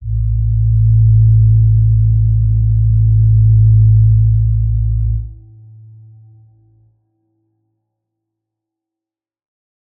G_Crystal-A2-f.wav